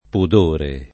pudore [ pud 1 re ] s. m.